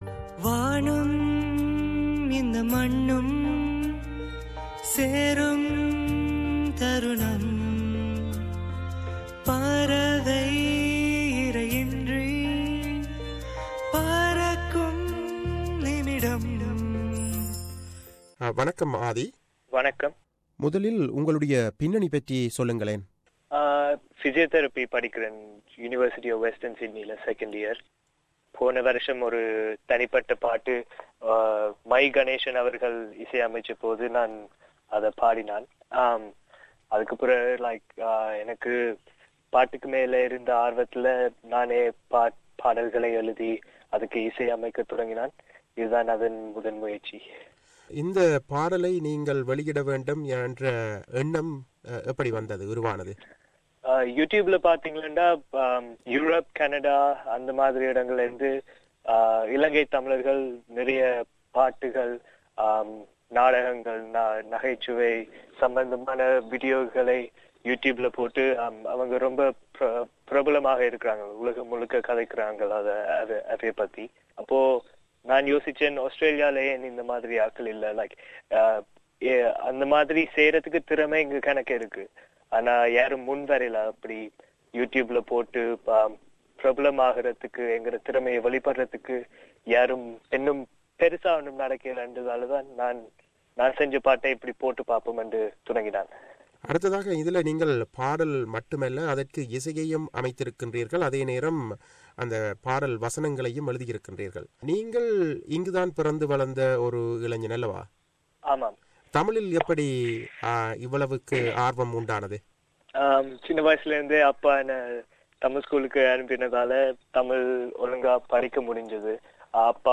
Piano
Violin and Backing Vocals
Lead and Rhythm Guitar
Bass Guitar
Percussion